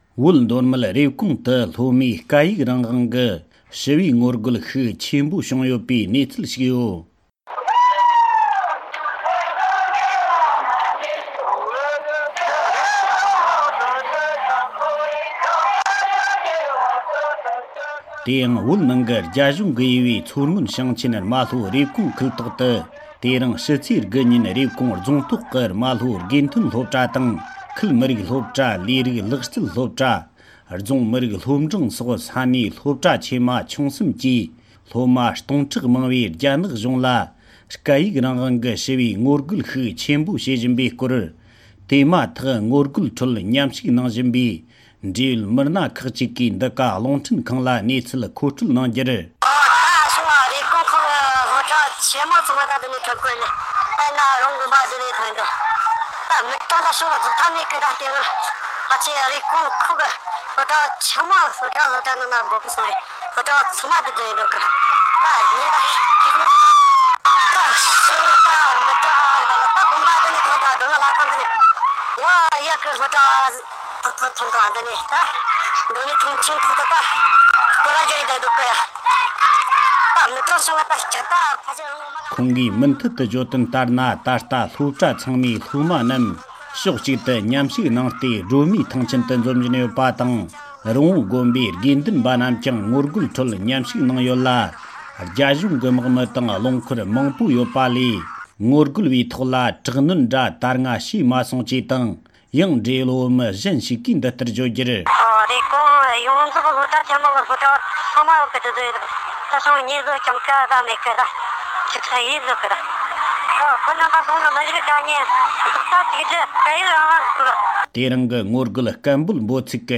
སྒྲ་ལྡན་གསར་འགྱུར།
༄༅༎ཕྱི་ལོ་ ༢༠༡༢ ཟླ་ ༡༡ ཚེས་ ༩ ཉིན། མདོ་སྨད་རེབ་གོང་དུ་སློབ་མ་སྟོང་ཕྲག་མང་པོས་སྐད་ཡིག་རང་དབང་དགོས་པའི་ངོ་རྒོལ་ཤུགས་ཆེན་པོ་ཞིག་བྱུང་ཡོད་པའི་སྐོར། ངོ་རྒོལ་ནང་དུ་མཉམ་ཞུགས་གནང་བཞིན་པའི་བོད་མི་ཁག་གཅིག་གིས་འདི་ག་རླུང་འཕྲིན་ཁང་དུ་གནས་ཚུལ་ངོ་སྤྲོད་གནང་བར་གསན་རོགས་ཞུ༎